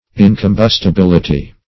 Search Result for " incombustibility" : The Collaborative International Dictionary of English v.0.48: Incombustibility \In`com*bus`ti*bil"i*ty\, n. [Cf. F. incombustilit['e].]
incombustibility.mp3